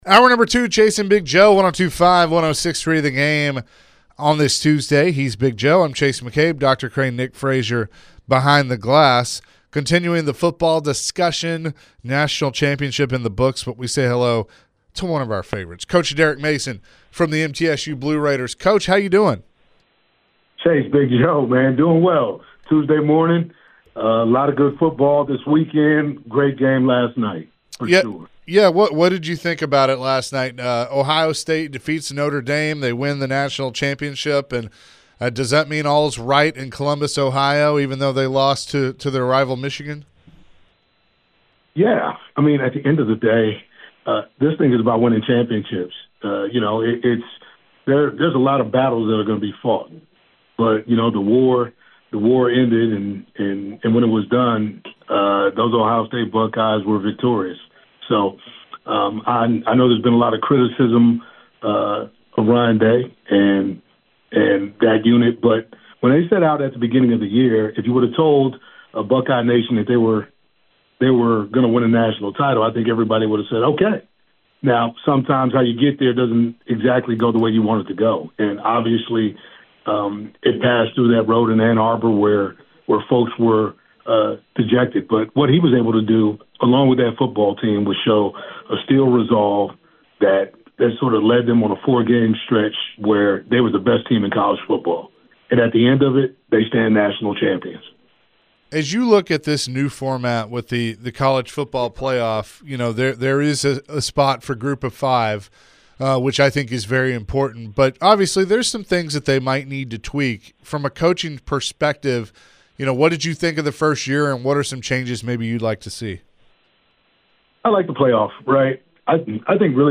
The guys catch up with MTSU Head Football Coach Derek Mason discussing the recent national championship game. Coach Mason mentioned how busy his program has been already this offseason.